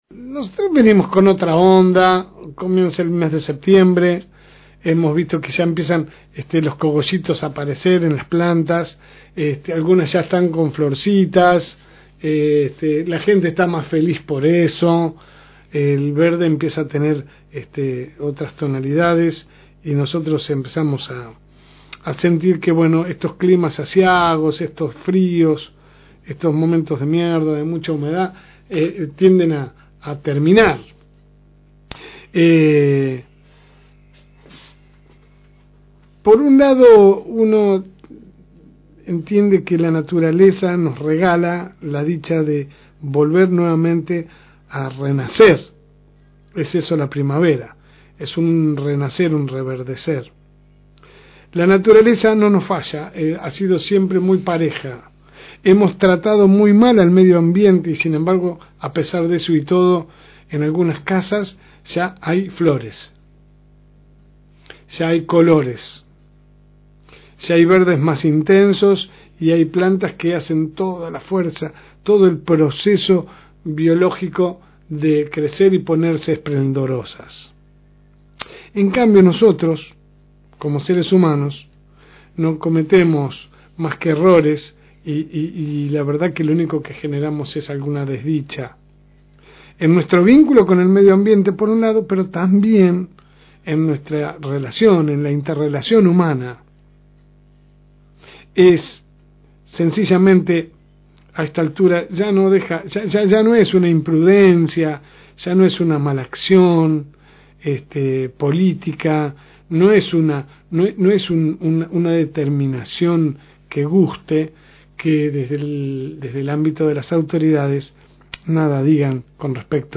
AUDIO – Editorial de La Segunda Mañana – FM Reencuentro